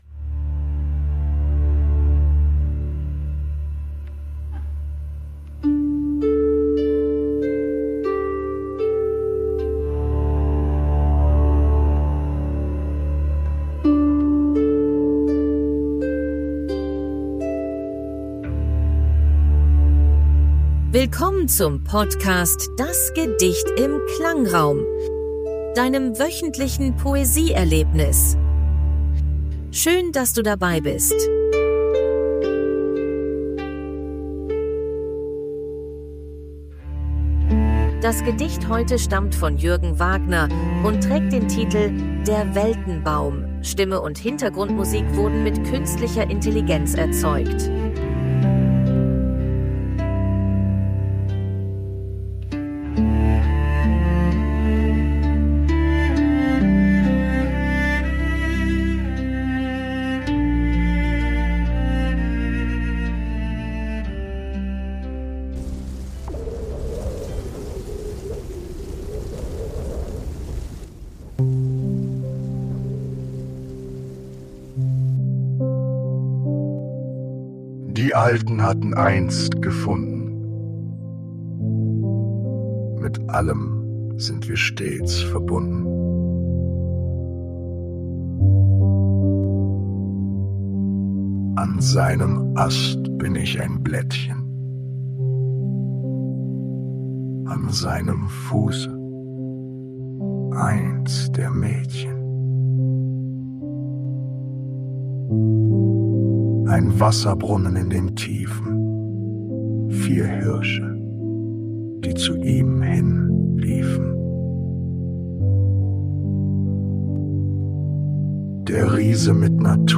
Stimme und Hintergrundmusik
wurden mit KI erzeugt. 2025 GoHi (Podcast) - Kontakt: